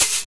PERC.105.NEPT.wav